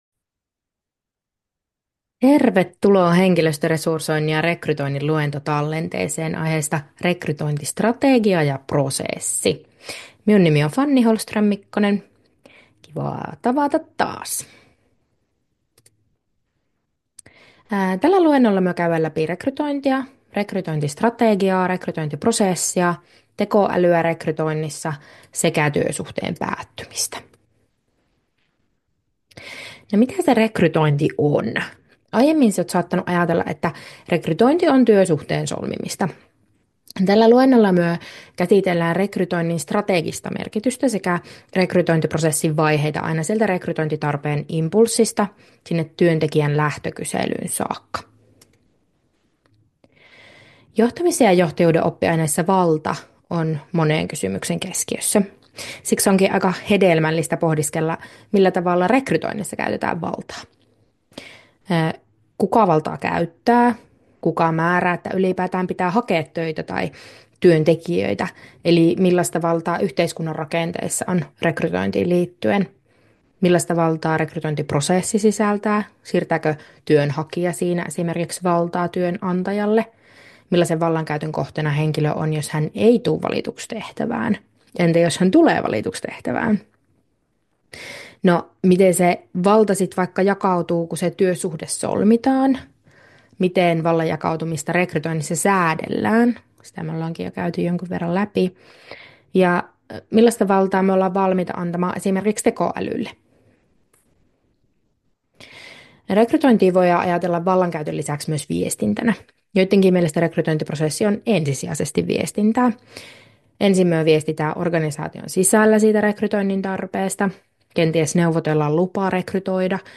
YJOA2220 Henkilöstöresursointi ja rekrytointi, luentotallenne aiheesta rekrytointistrategia ja -prosessi.